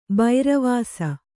♪ bairavāsa